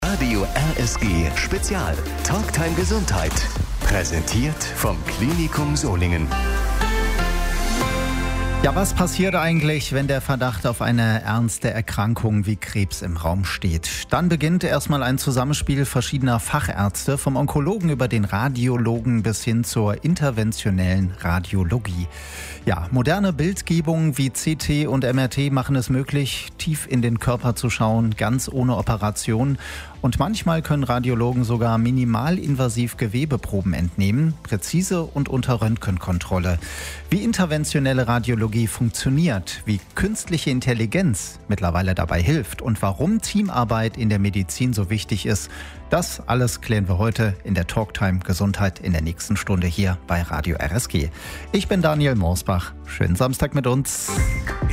Die Radiosprechstunde lief am Samstag, 25. Juli 2025, von 12 bis 13 Uhr bei Radio RSG und kann hier nachgehört werden.